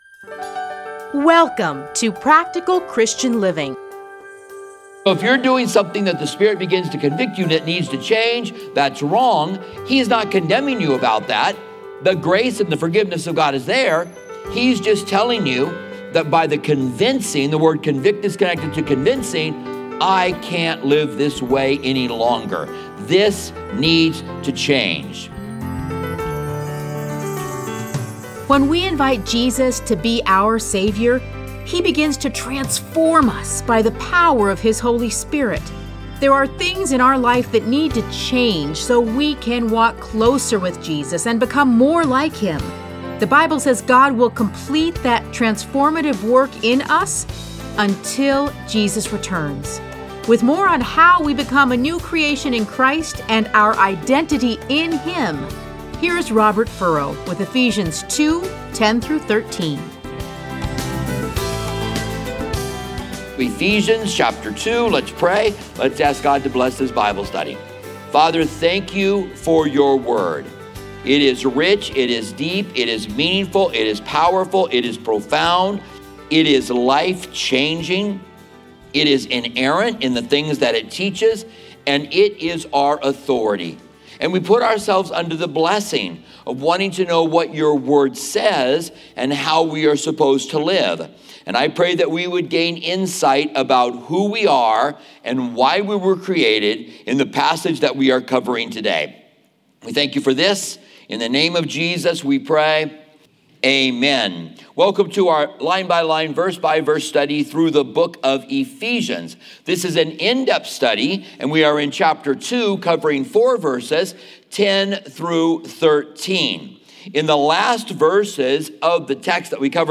Listen to a teaching from Ephesians 2:10-13.